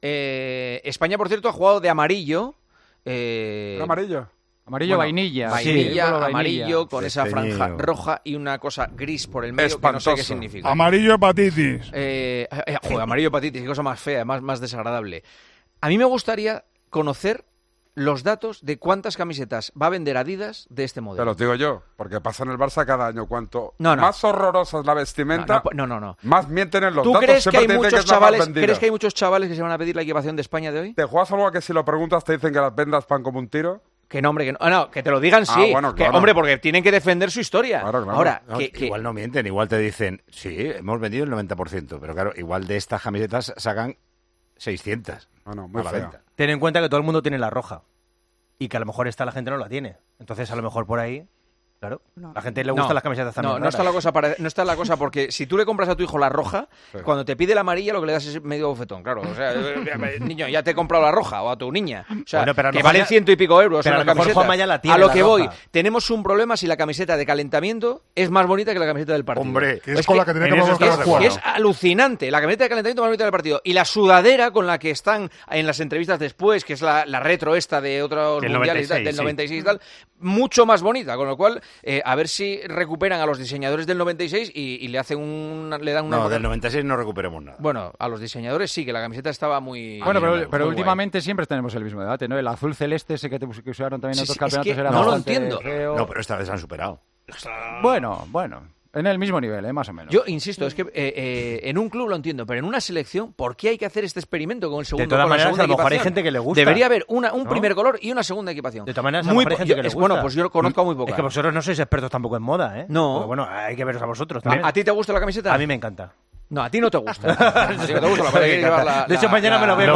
La Selección española ha jugado con su segunda equipación y muchos aficionados han alzado la voz en contra del diseño y del color. Juanma y otros tertulianos opinan muy parecido.